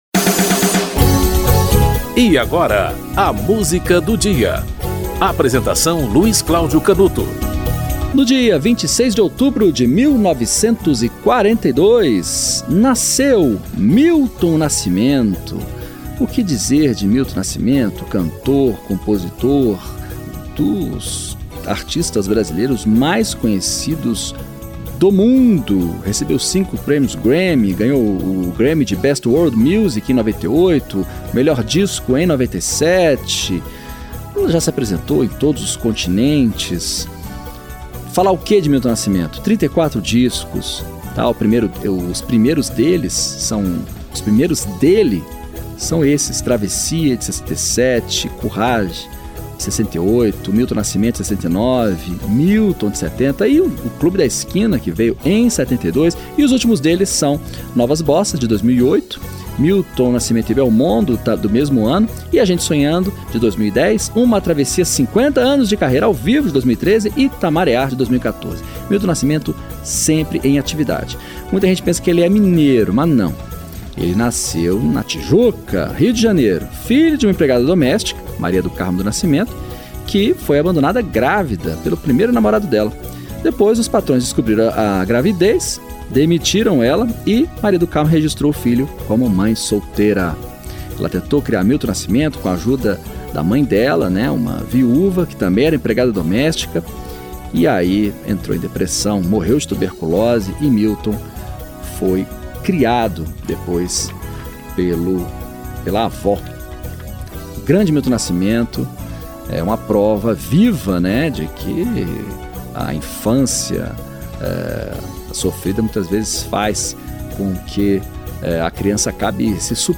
Beto Guedes e Milton Nascimento - Nada Será Como Antes (Milton Nascimento e Ronaldo Bastos)